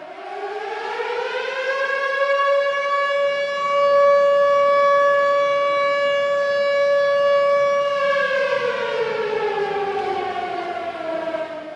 Siren.ogg